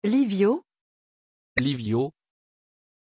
Aussprache von Lyvio
pronunciation-lyvio-fr.mp3